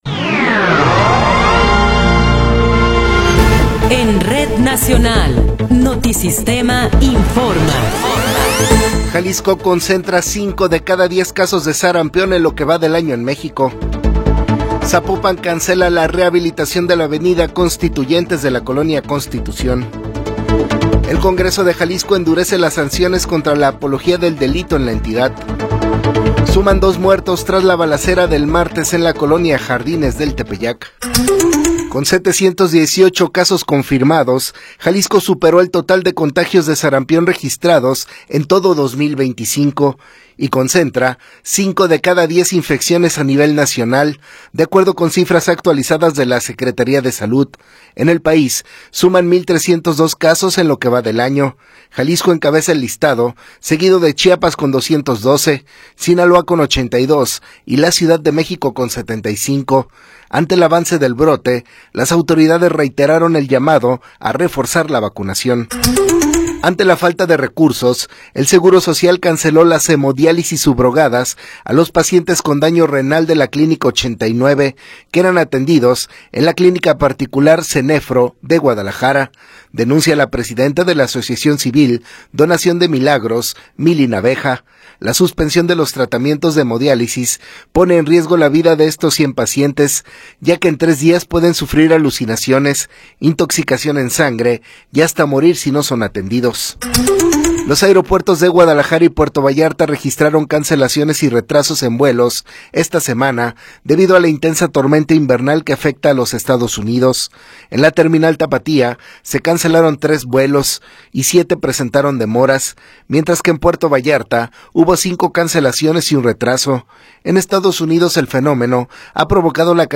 Noticiero 9 hrs. – 29 de Enero de 2026